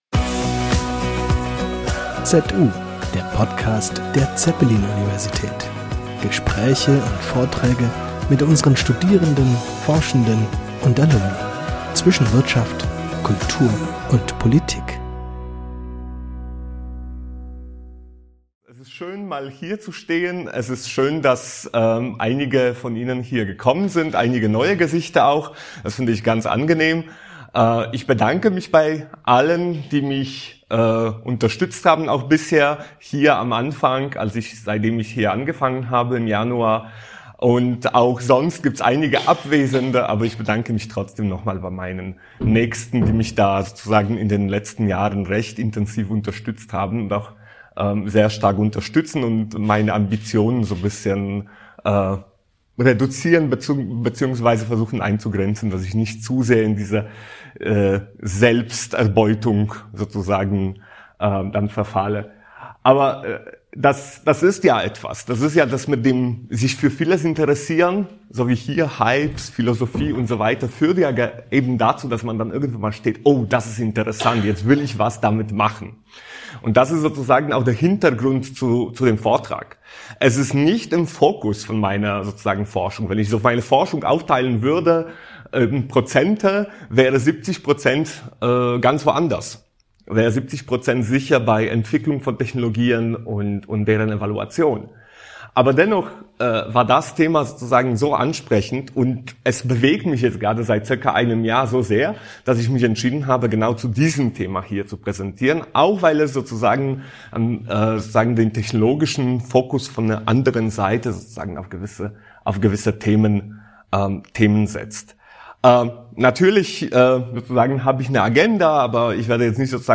| Antrittsvorlesung ~ ZU - Der Podcast der Zeppelin Universität Podcast